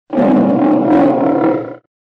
Our Lion from 'Aesop's Fables' roars!